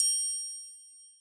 triangle 2.wav